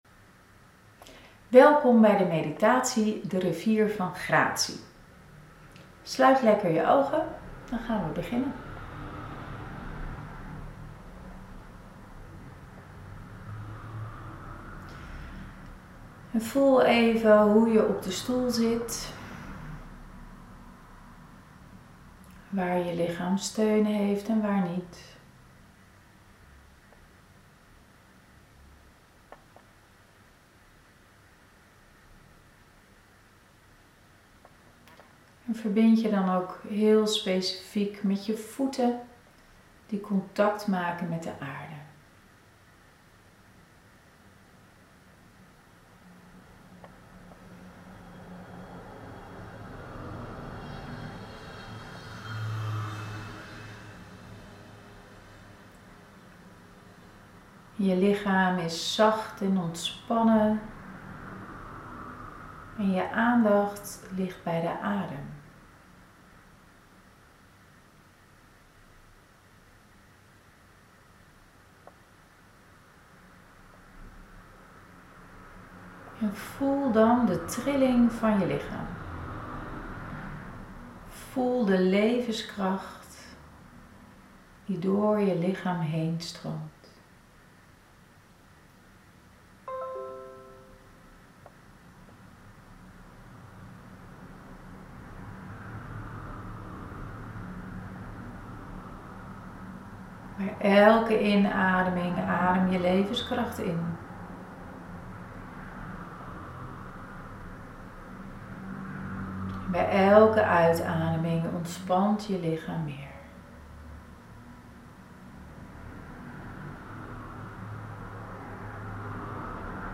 8.1 Meditatie: Rivier van gratie
8.-Meditatie-AUDIO-GK.mp3